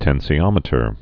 (tĕnsē-ŏmĭ-tər)